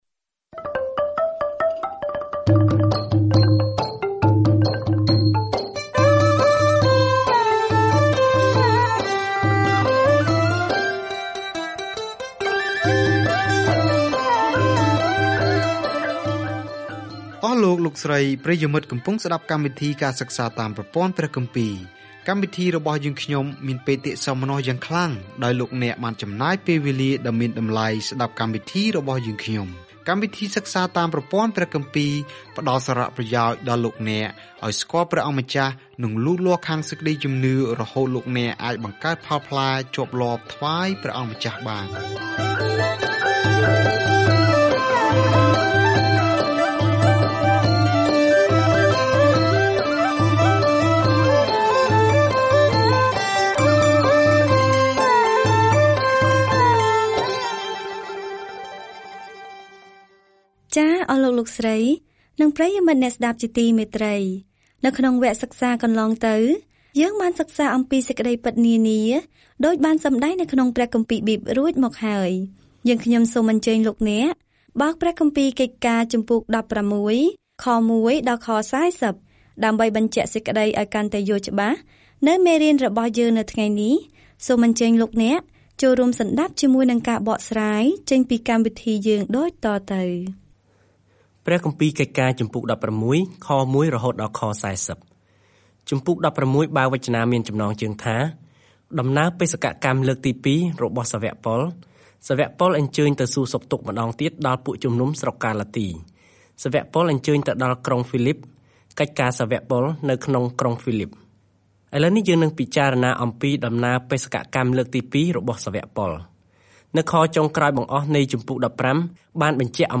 កិច្ចការរបស់ព្រះយេស៊ូវចាប់ផ្ដើមនៅក្នុងសៀវភៅដំណឹងល្អឥឡូវនេះបន្តតាមរយៈព្រះវិញ្ញាណរបស់ទ្រង់ ខណៈដែលព្រះវិហារត្រូវបានគេដាំនិងរីកលូតលាស់ពាសពេញពិភពលោក។ ការធ្វើដំណើរជារៀងរាល់ថ្ងៃតាមរយៈកិច្ចការ នៅពេលអ្នកស្តាប់ការសិក្សាជាសំឡេង ហើយអានខគម្ពីរដែលជ្រើសរើសពីព្រះបន្ទូលរបស់ព្រះ។